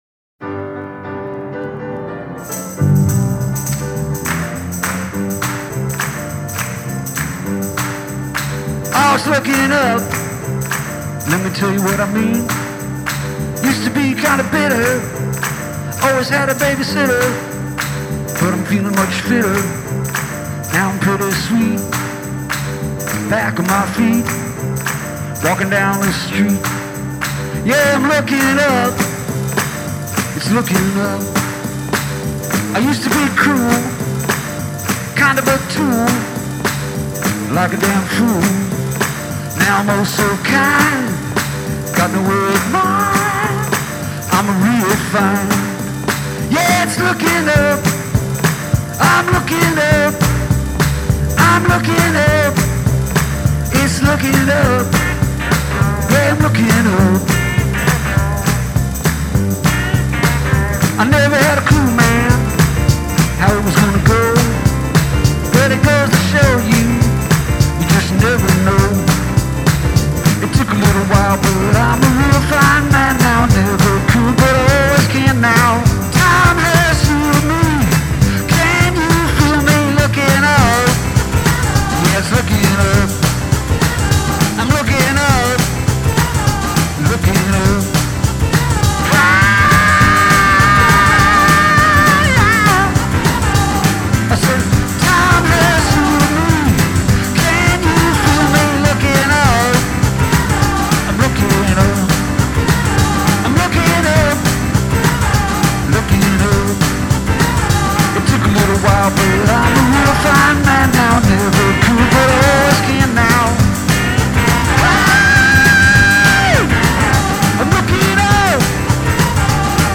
gospel influenced affair with handclaps for percussion